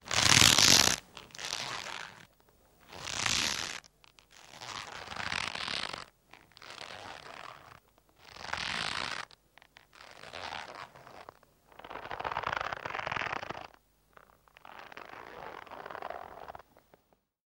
Звуки казни
Шорох качающегося тела, натяжение веревки